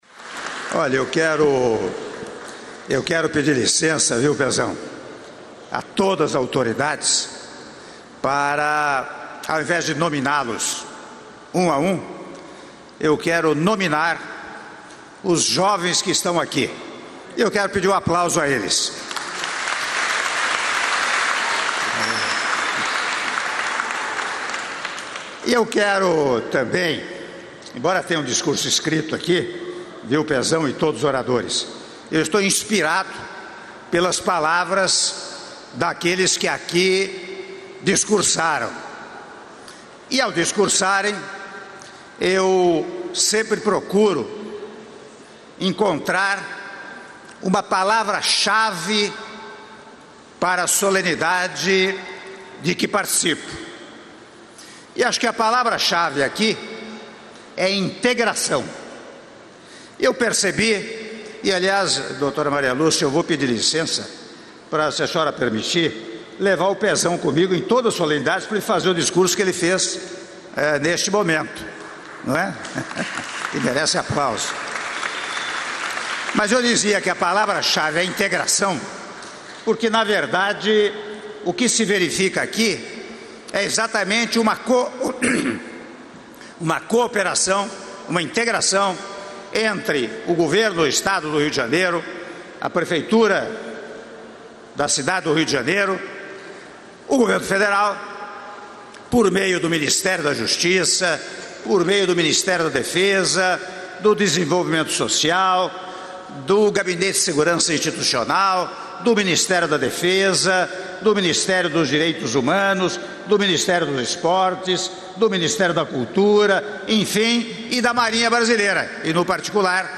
Áudio do discurso do Presidente da República, Michel Temer, durante Cerimônia de lançamento do Programa Emergencial de Ações Sociais para o Estado do Rio de Janeiro e seus Municípios - (07min25s) - Rio de Janeiro/RJ — Biblioteca